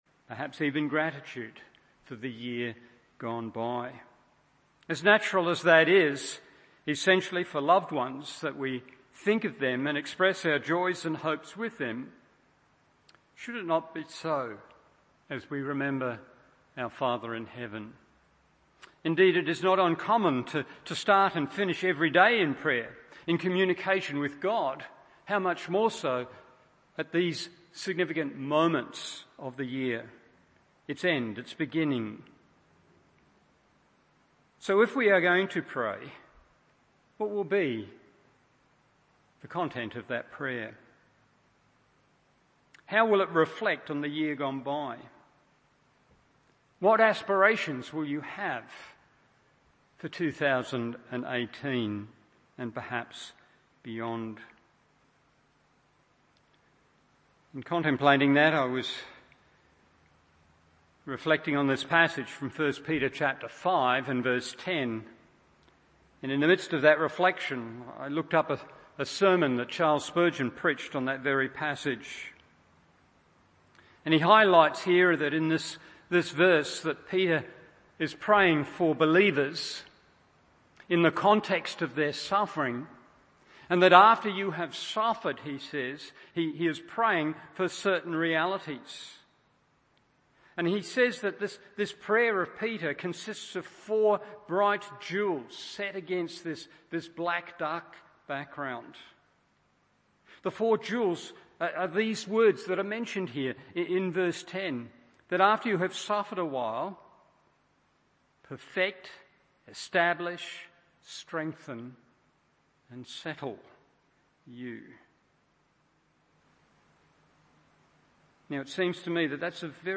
Evening Service 1 Peter 5:10 1. Perfection 2. Establishment 3.